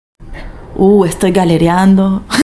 galerear_prononciation02_3.mp3